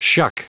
Prononciation du mot shuck en anglais (fichier audio)
Prononciation du mot : shuck